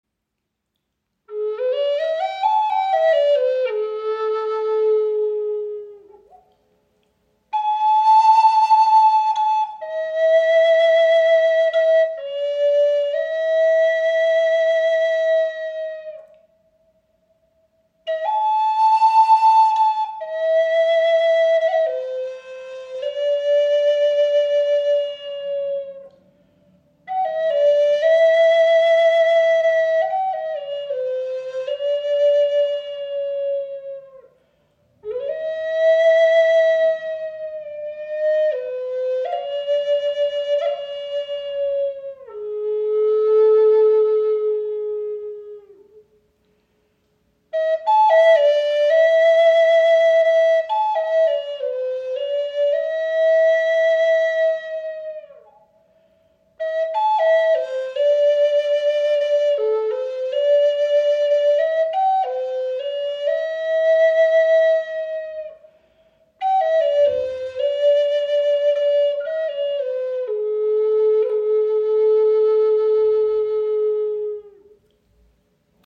Die Sparrow Hawk Flöte in A-Moll (440 Hz) ist eines unserer beliebtesten Modelle. Sie wird aus einem einzigen Stück spanischer Zeder gefertigt – ein weiches Holz mit warmer Klangfarbe, das klare Höhen und kraftvolle Tiefen erzeugt.